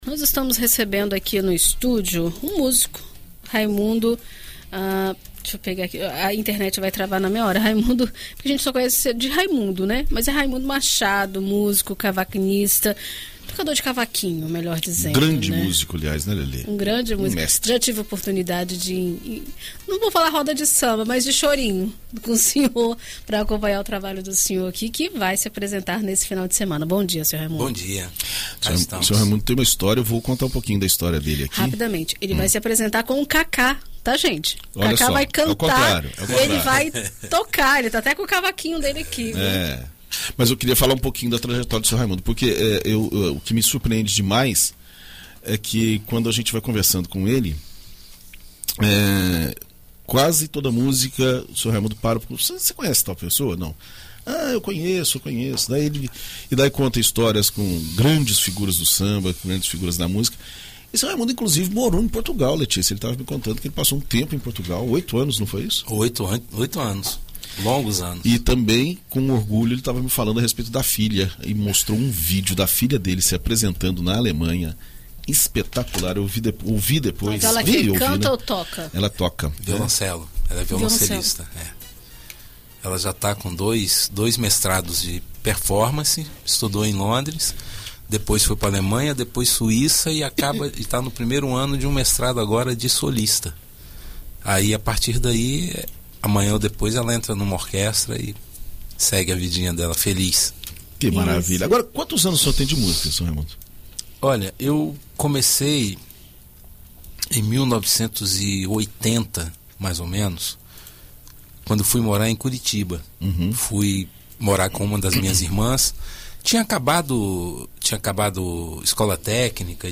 Em entrevista à BandNews FM Espírito Santo nesta sexta-feira (22)